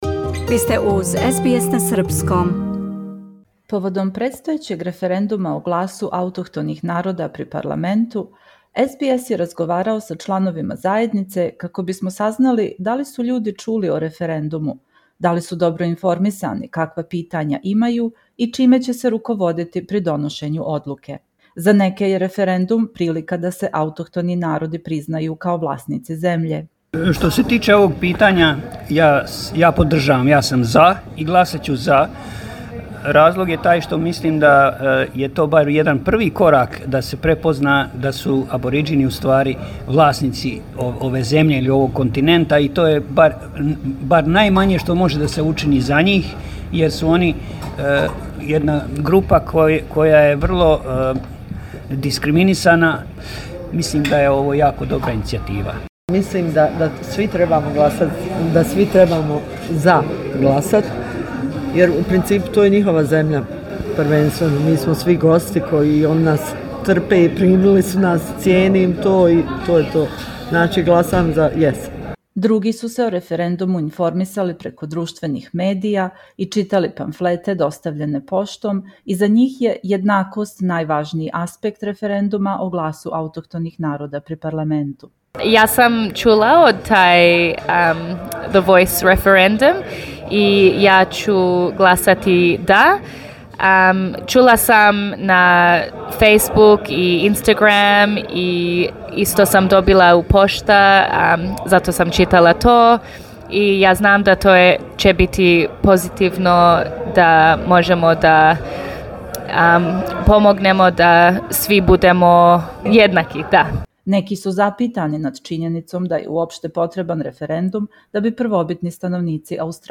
vox-pop-podcast.mp3